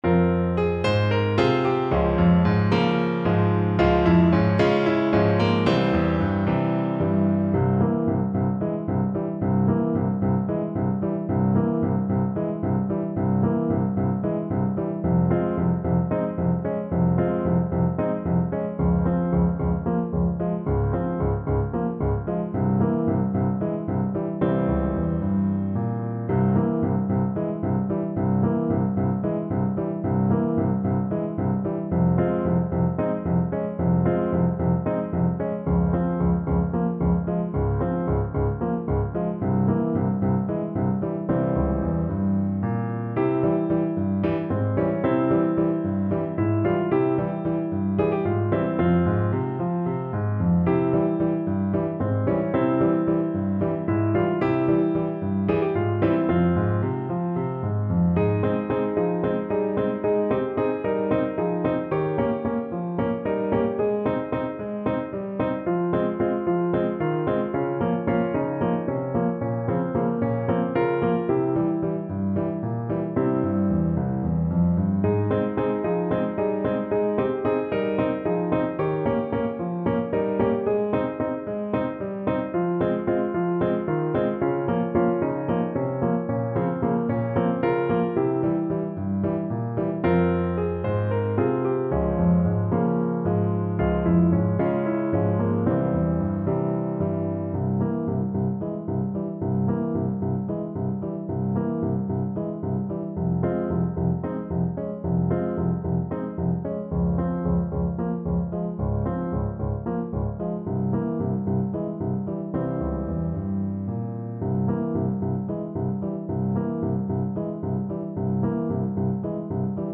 Trombone
7/8 (View more 7/8 Music)
Bb3-Eb5
F minor (Sounding Pitch) (View more F minor Music for Trombone )
Moderato = 112
Serbian